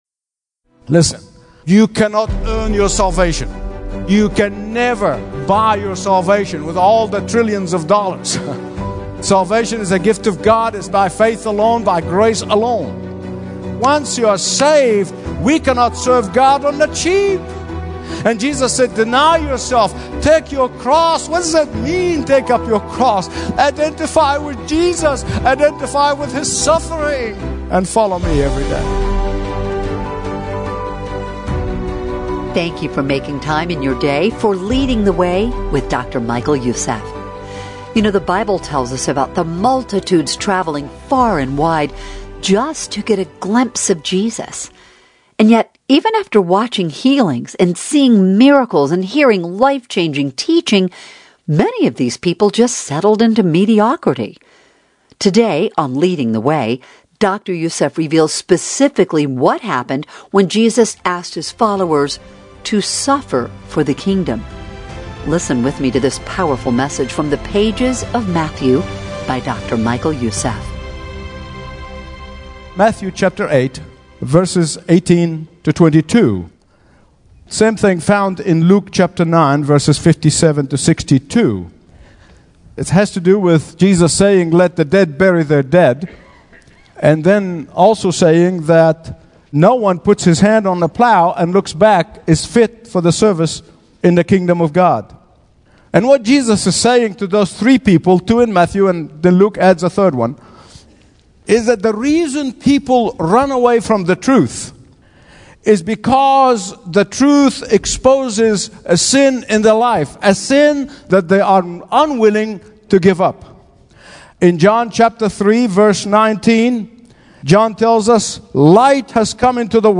Stream Expository Bible Teaching & Understand the Bible Like Never Before.
In this sobering message, Dr. Michael Youssef proclaims the uncompromising Truth of God’s Word: salvation is by grace alone through faith alone—but discipleship demands surrender. From Matthew 8 and Luke 9, Jesus, the divine Son of Man, confronts those who claim to follow Him yet cling to comfort, wealth, or divided loyalty.